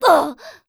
cleric_f_voc_hit_c.wav